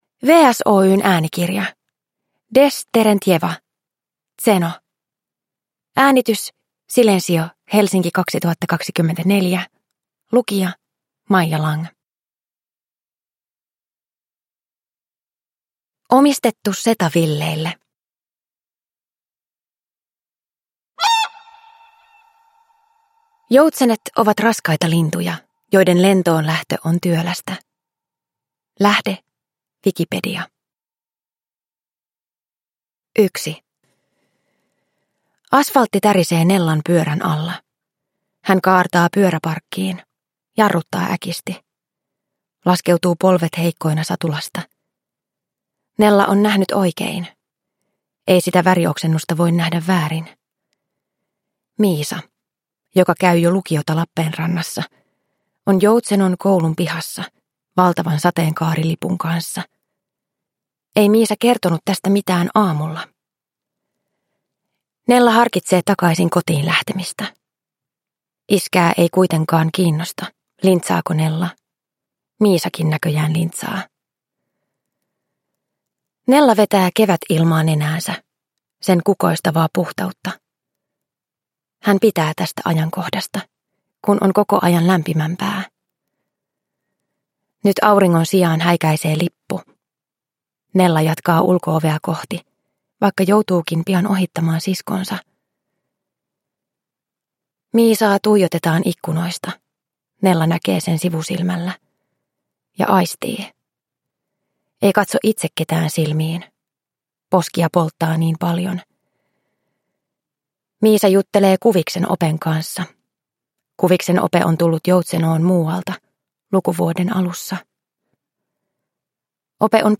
Zeno – Ljudbok